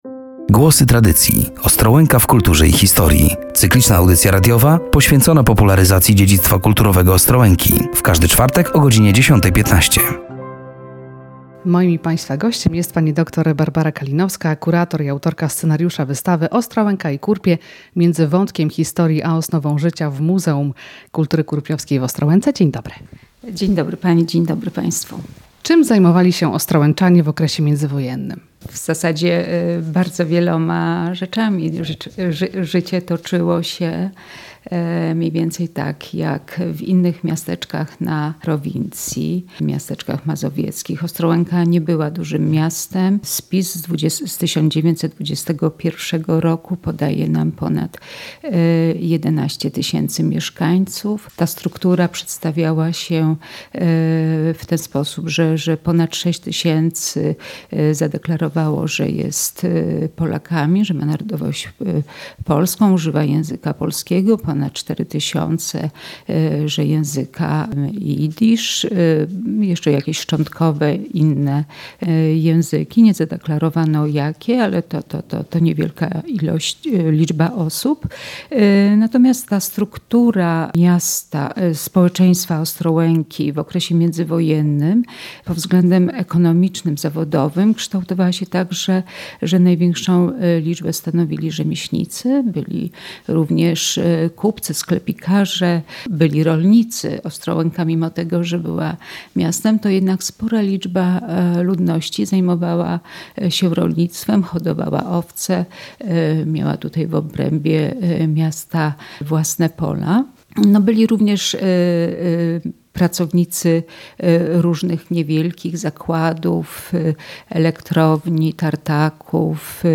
Do rozmowy zaprosiliśmy